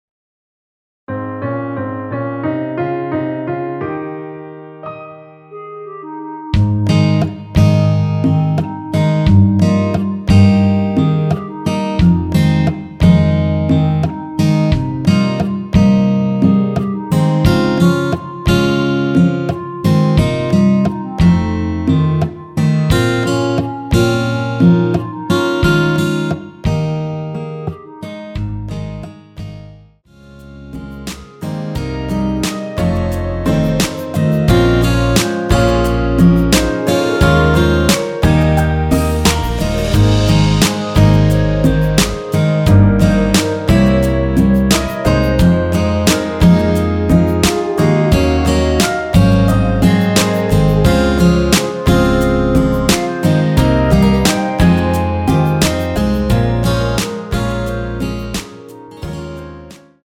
원키에서(-1)내린 멜로디 포함된 MR이며 여자파트 멜로디는 없습니다.(미리듣기 참조)
Ab
앞부분30초, 뒷부분30초씩 편집해서 올려 드리고 있습니다.
중간에 음이 끈어지고 다시 나오는 이유는
(멜로디 MR)은 가이드 멜로디가 포함된 MR 입니다.